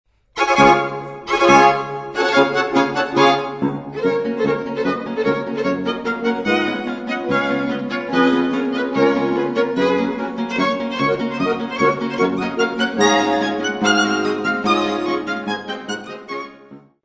** Quartett mit Knopfharmonika
Steinerner Saal, Musikverein Wien